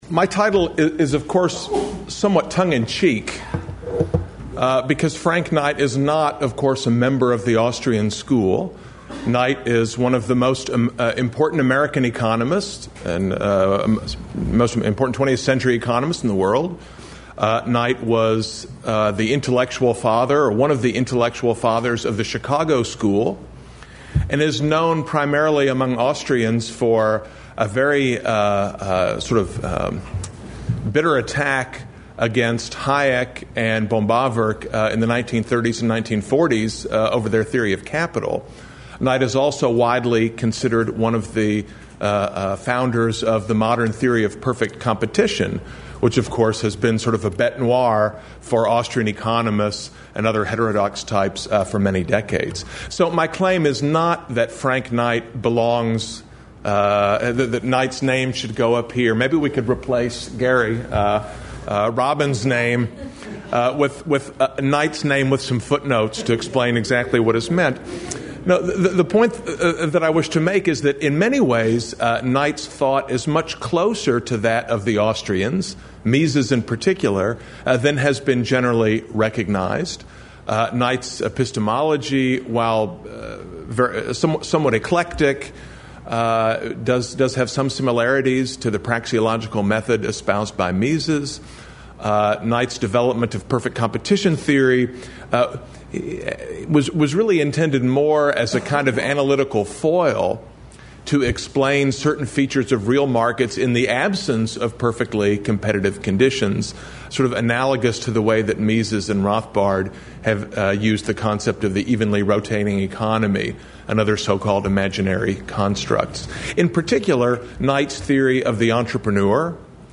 Session on Studies in Intellectual History. Recorded March 11, 2011, at the Ludwig von Mises Institute in Auburn, Alabama.